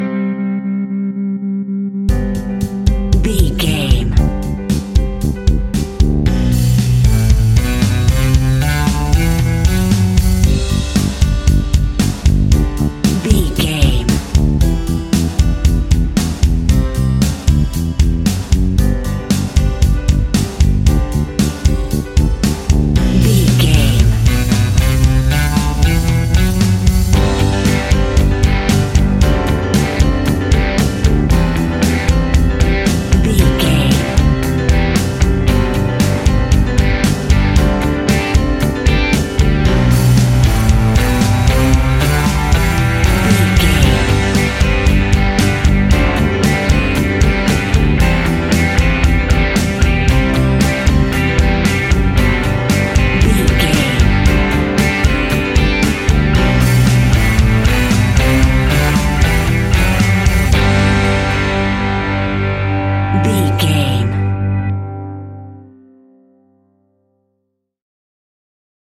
Ionian/Major
fun
energetic
uplifting
acoustic guitars
drums
bass guitar
electric guitar
piano
organ